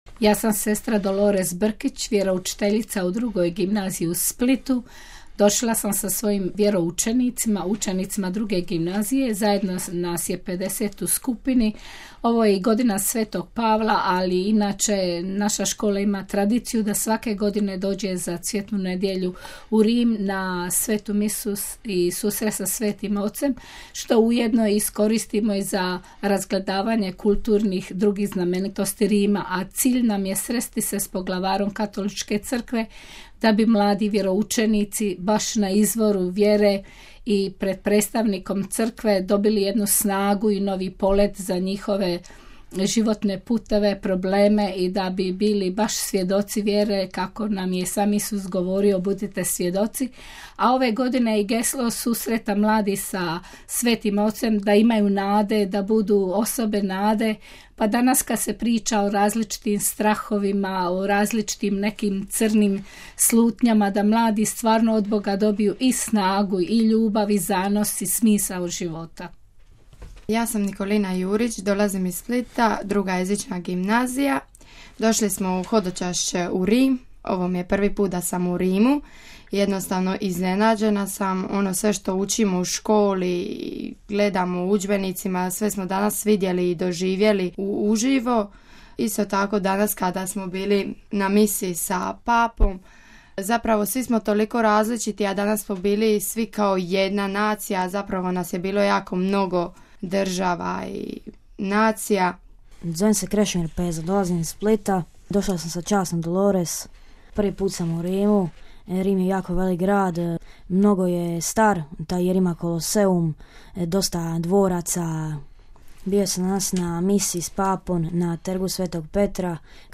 Među brojnim hodočasnicima i mladima iz cijeloga svijeta na jučerašnjoj misi na trgu svetoga Petra bilo je hodočasnika iz Hrvatske, te skupine mladih iz Splita i Zadra. S nekima smo razgovarali nakon mise, poslušajte njihove dojmove: RealAudio